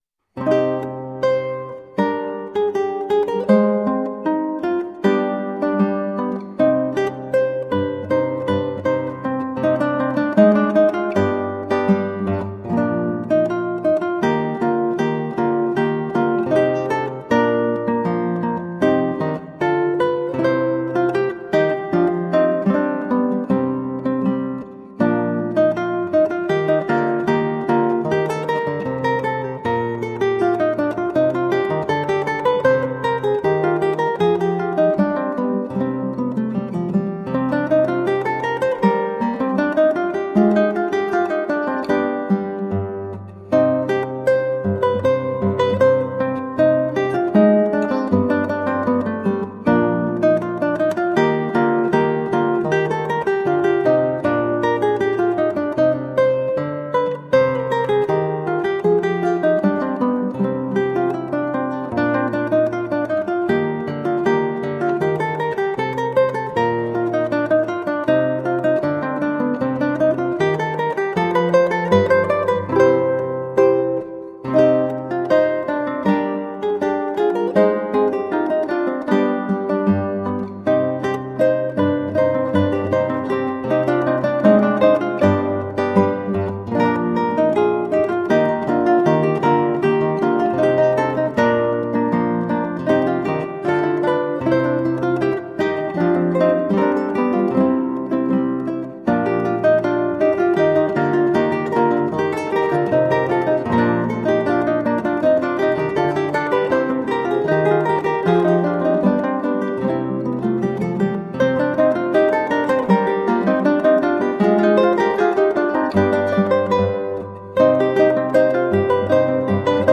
Colorful classical guitar.
played on 8-string guitar